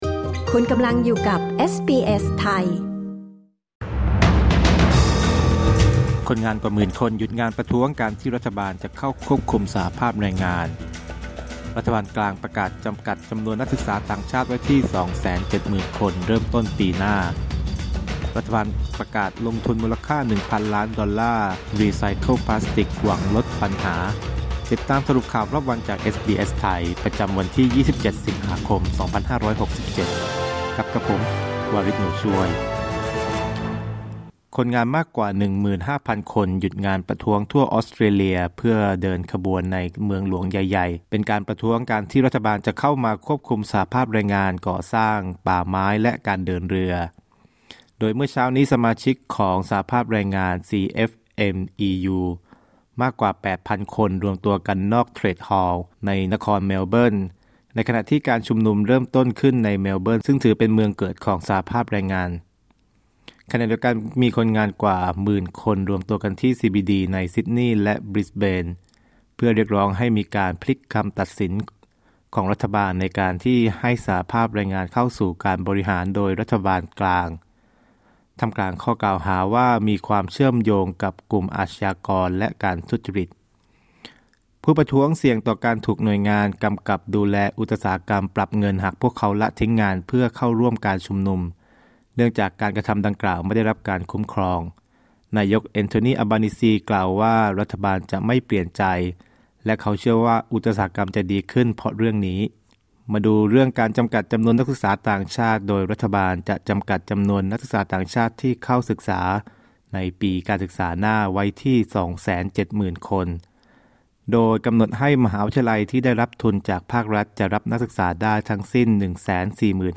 สรุปข่าวรอบวัน 27 สิงหาคม 2567